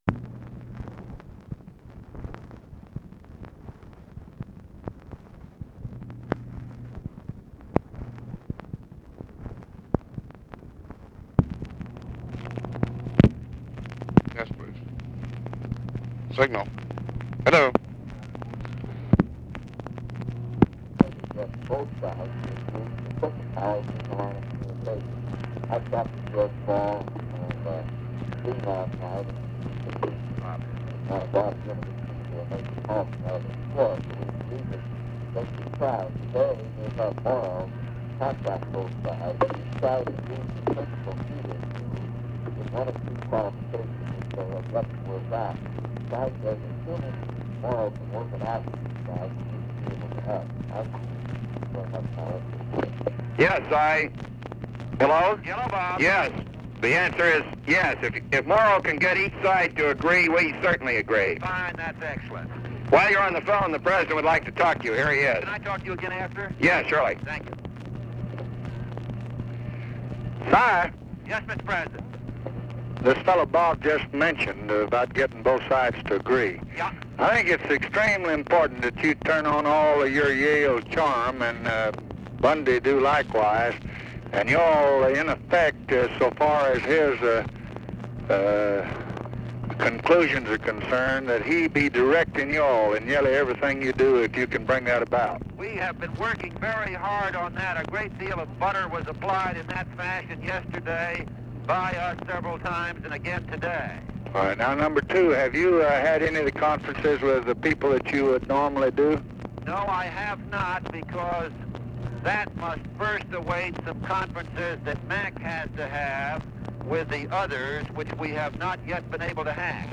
Conversation with CYRUS VANCE, OFFICE CONVERSATION, ROBERT MCNAMARA and MCGEORGE BUNDY, May 23, 1965
Secret White House Tapes